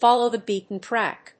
アクセントfóllow [kéep to] the béaten tráck